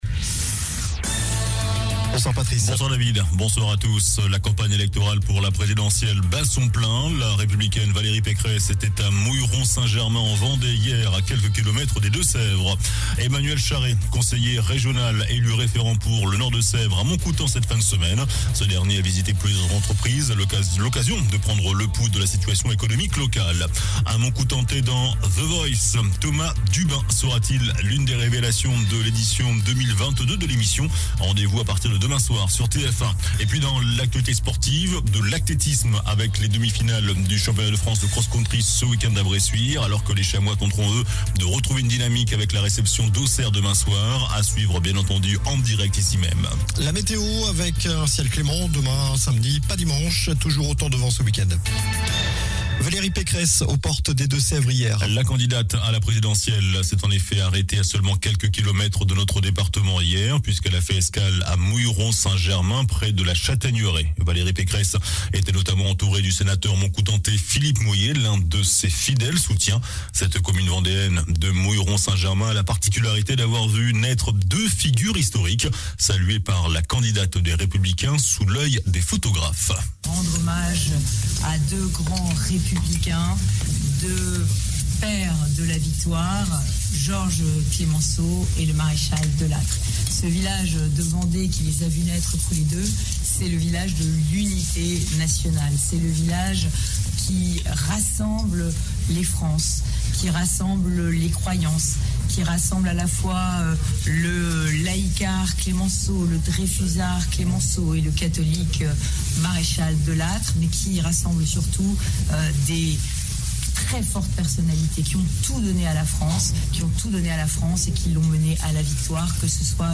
JOURNAL DU VENDREDI 18 FEVRIER ( SOIR )